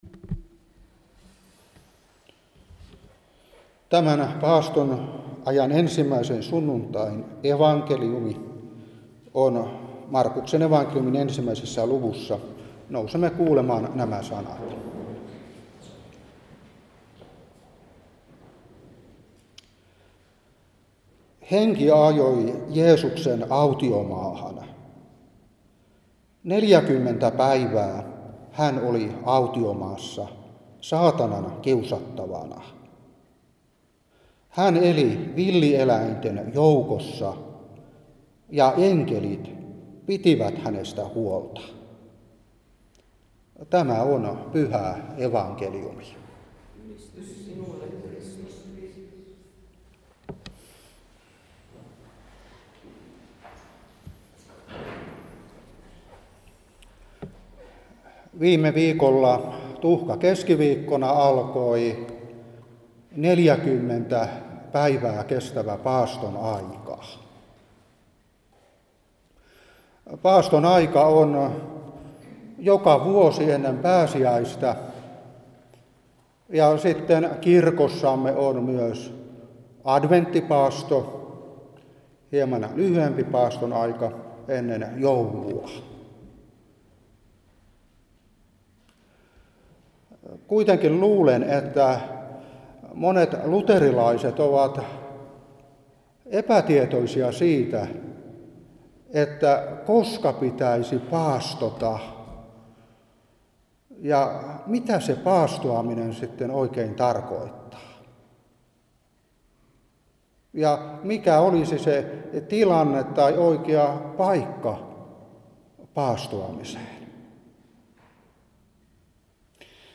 Saarna 2021-1.